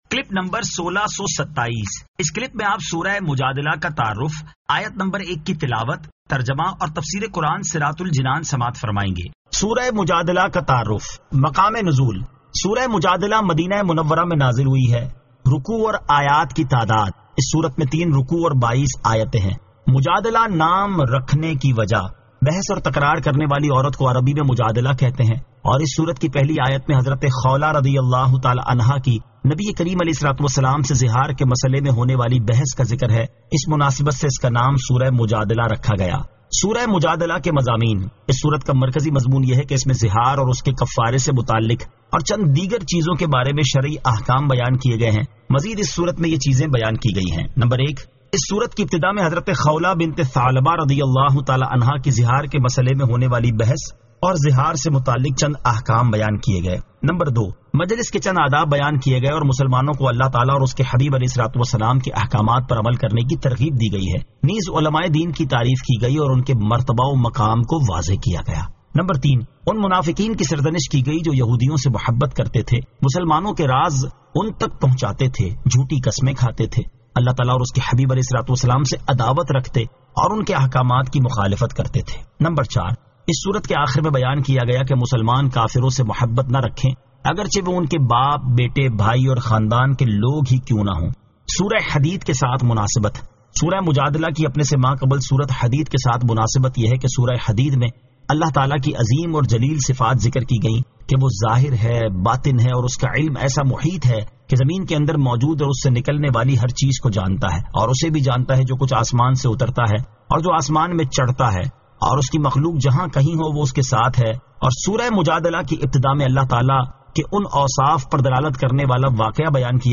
Surah Al-Mujadila 01 To 01 Tilawat , Tarjama , Tafseer